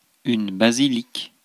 Ääntäminen
IPA: [ba.zi.lik]